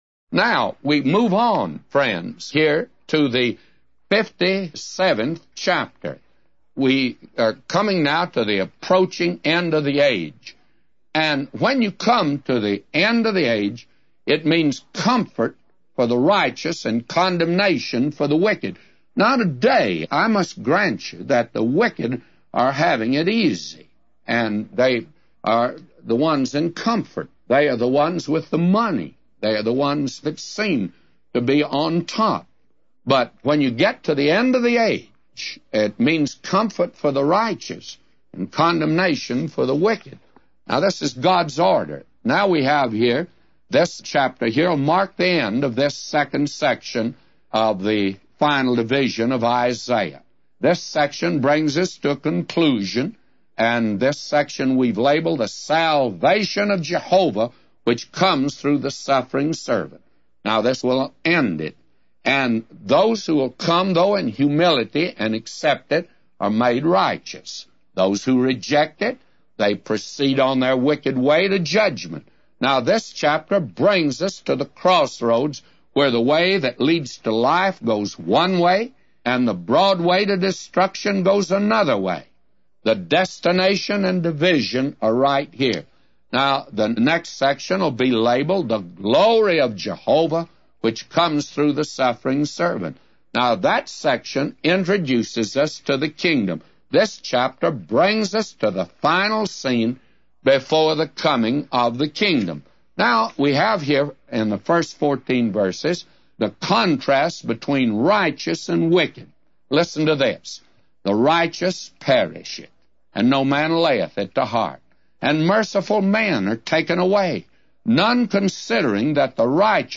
A Commentary By J Vernon MCgee For Isaiah 57:1-999